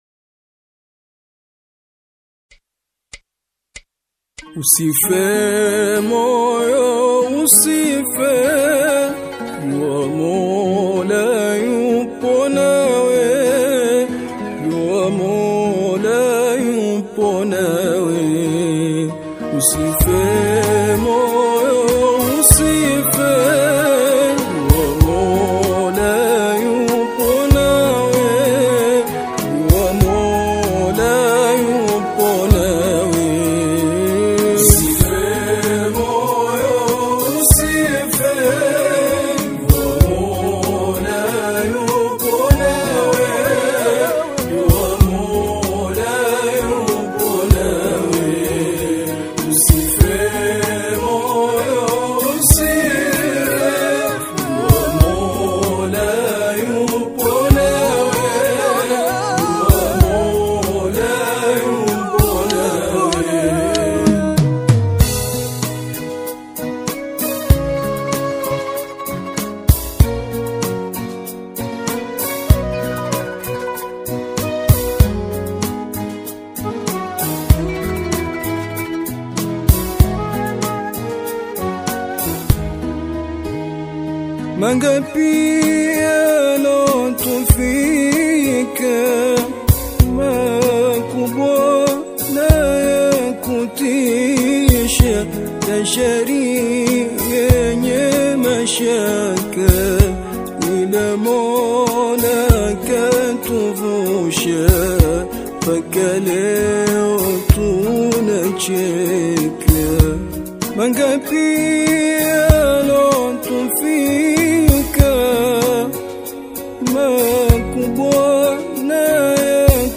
qaswida
heartfelt vocal delivery